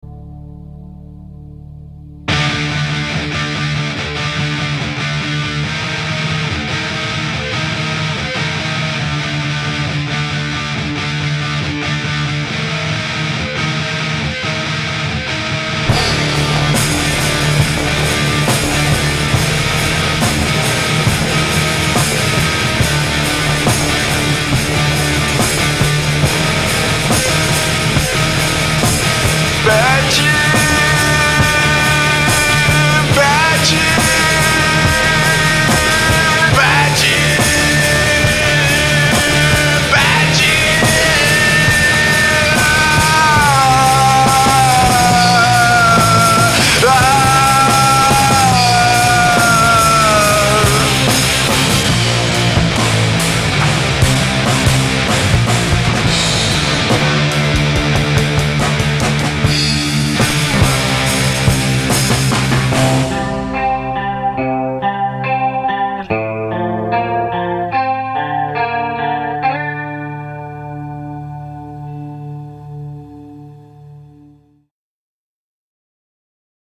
I like to finish off my editing after I'm done mixing so you might hear some double transients and out-of-tempo hits in there. Basically right off the bat it felt like the drummer played lazily, but maybe my mix took care of that. I slapped a limiter on it and took a bit of the gunk off.